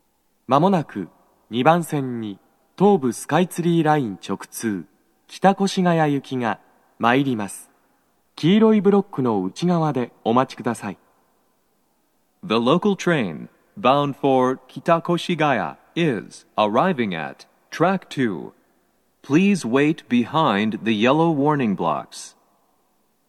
スピーカー種類 BOSE天井型
鳴動は、やや遅めです。
2番線 北千住・南栗橋方面 接近放送 【男声